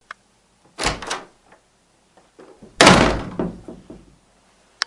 撞车
描述：舞台外的碰撞
标签： 阶段 防撞
声道立体声